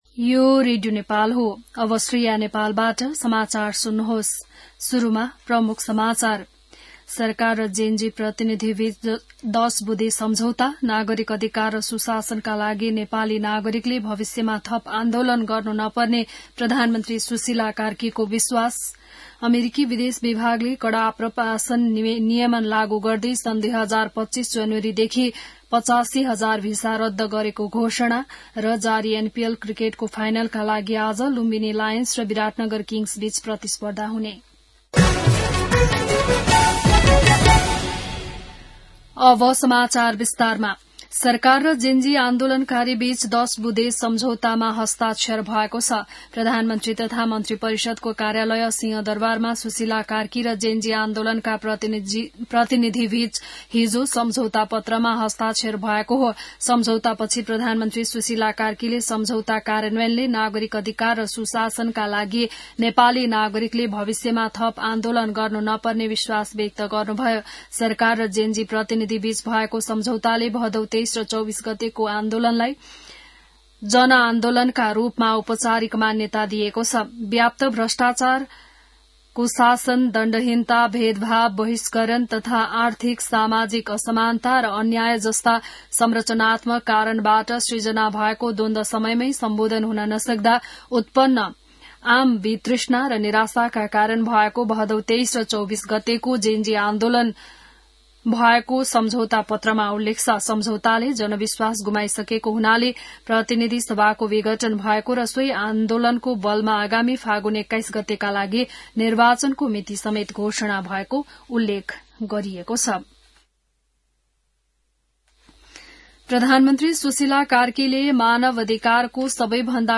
बिहान ९ बजेको नेपाली समाचार : २५ मंसिर , २०८२